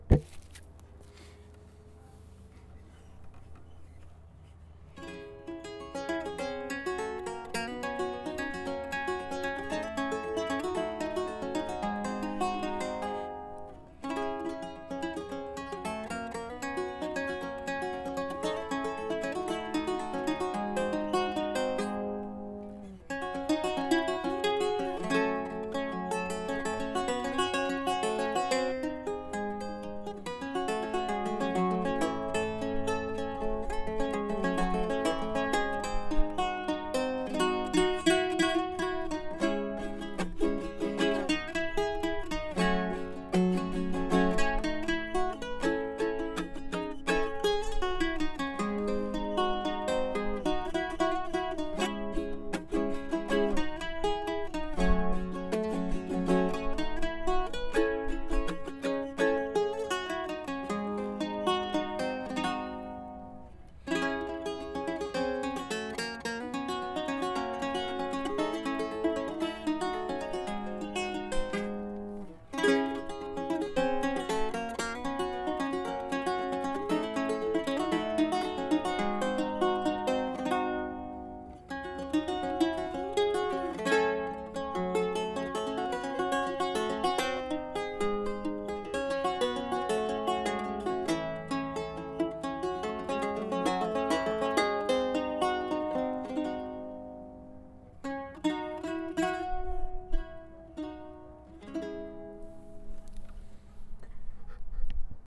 Audio samples of my ronroco: